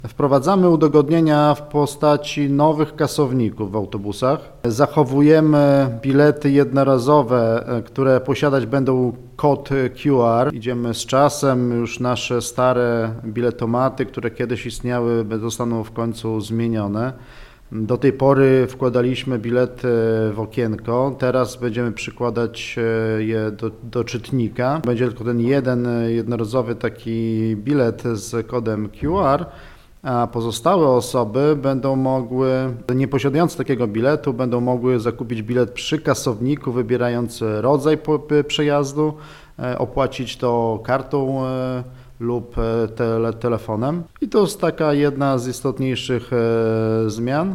Zamiast tego od 1 grudnia w autobusach pojawią się urządzenia, które pozwolą wskazać wybrany przejazd i zapłacić kartą oraz skasować bilet z kodem QR. O szczegółach w Suwalskim Magazynie Samorządowym mówił Adam Ołowniuk radny Koalicji Obywatelskiej.